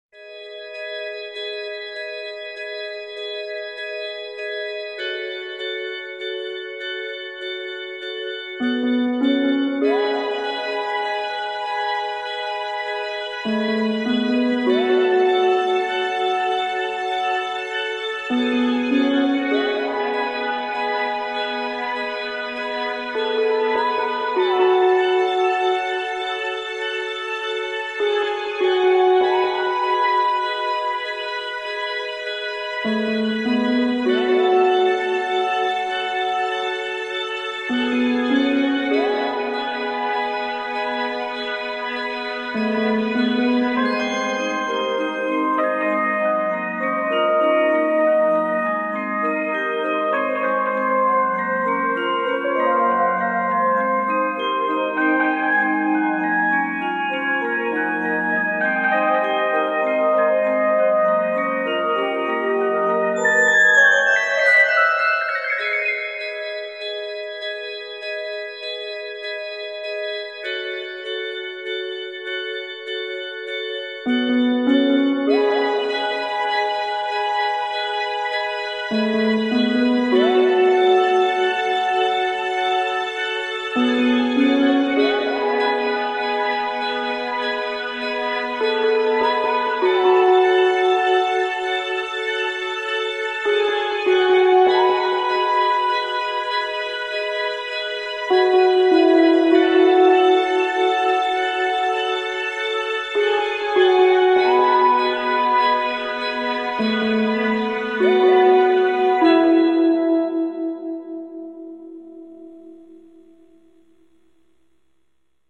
Музыка влюбленности для сказки в туманной дымке